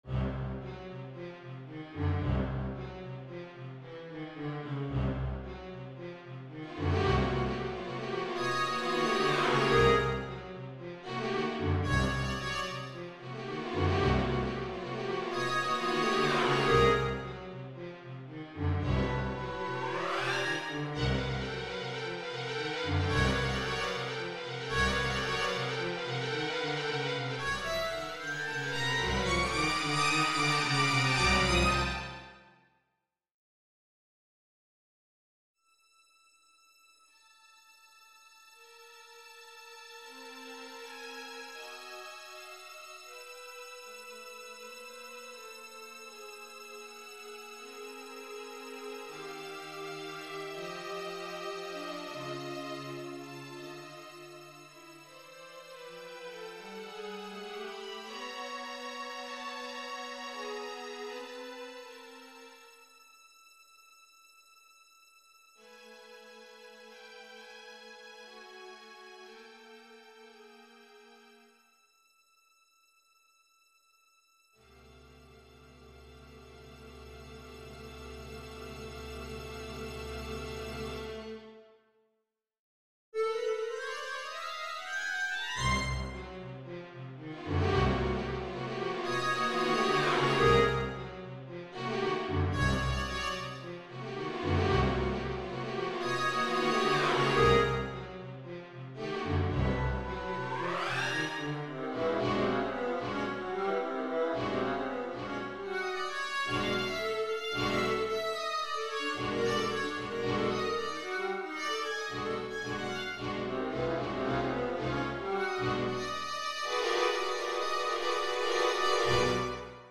for 4-part viola ensemble